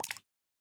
sounds / mob / frog / tongue3.ogg
tongue3.ogg